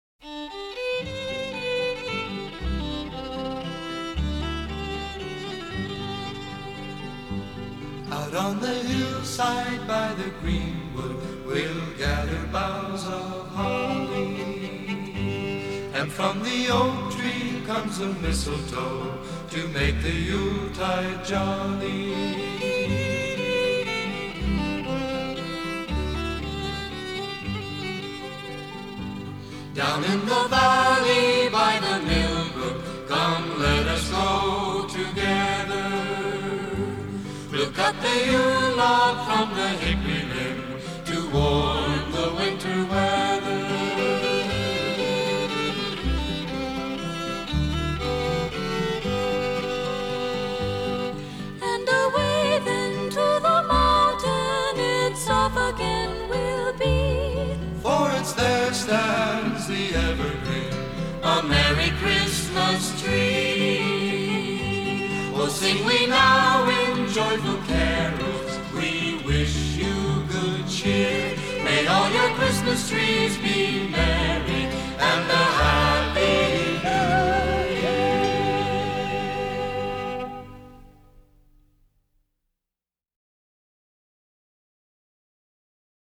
folk pop group